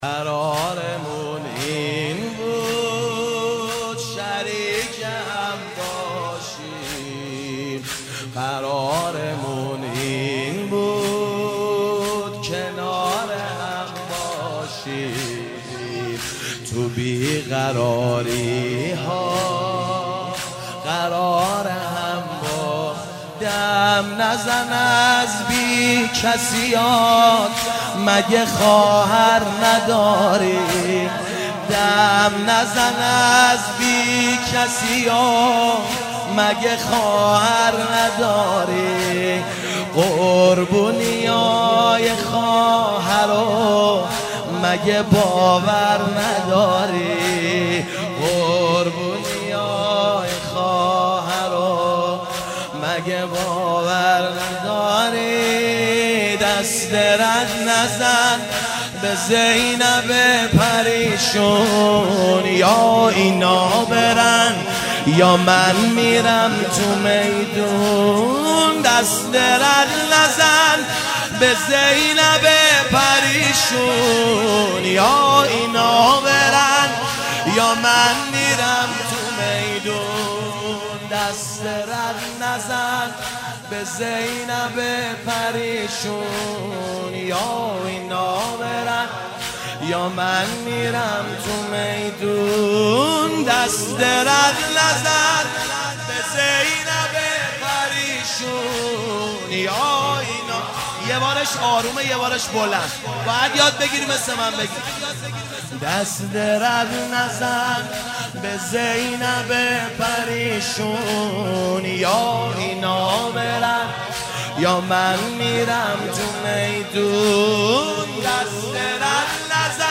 شب چهارم محرم 97 - زمینه - قرارمون این بود